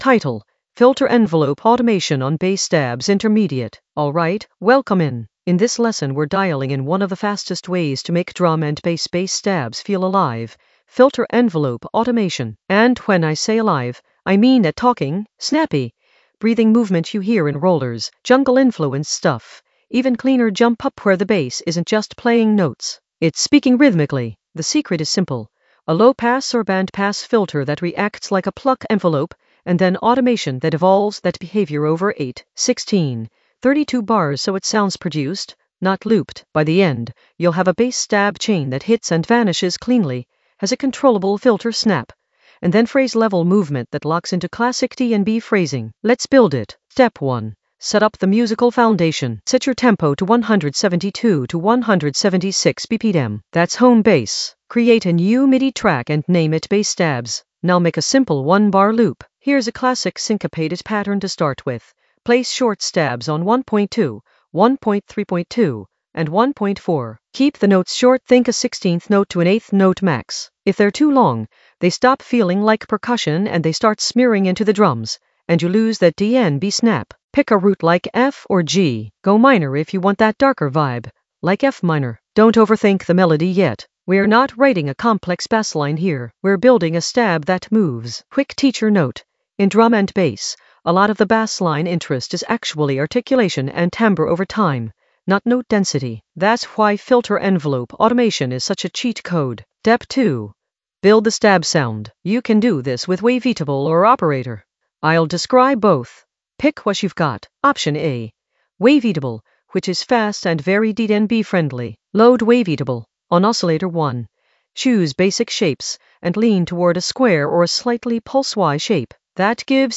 Narrated lesson audio
The voice track includes the tutorial plus extra teacher commentary.
An AI-generated intermediate Ableton lesson focused on Filter envelope automation on bass stabs in the Automation area of drum and bass production.